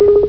Computer Tones - 4k